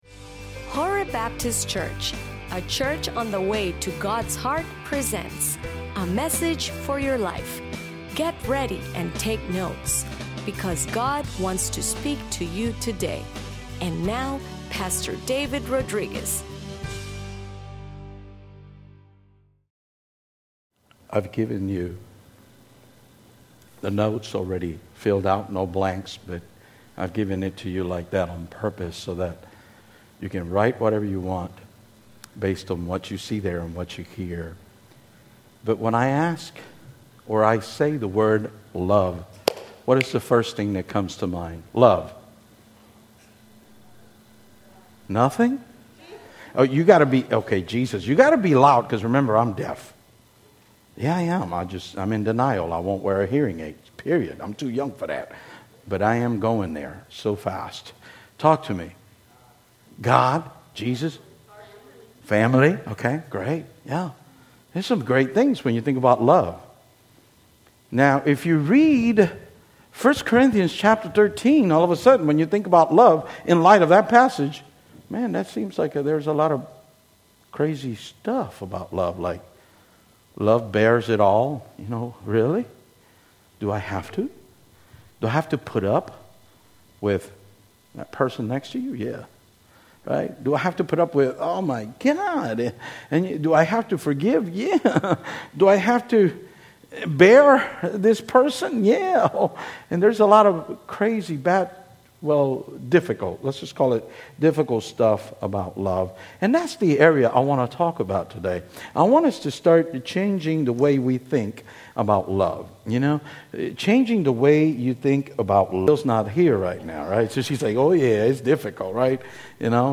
ingles-sermon.mp3